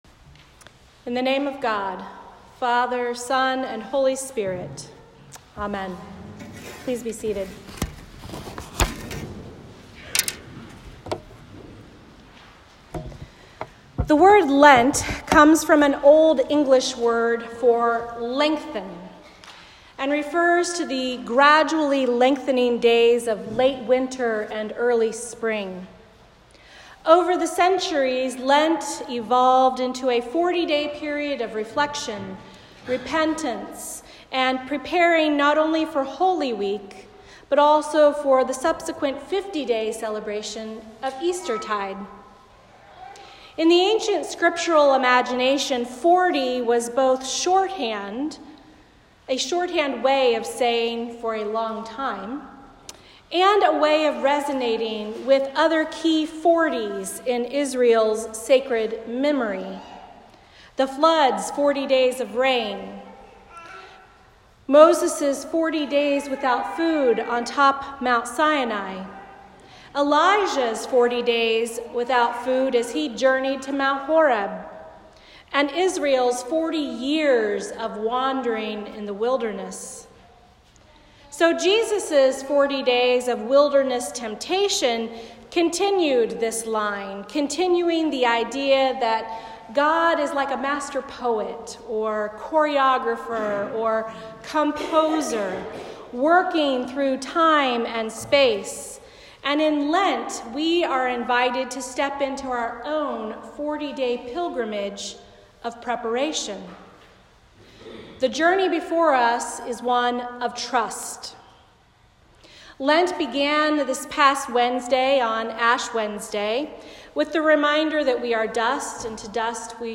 A Sermon for the First Sunday in Lent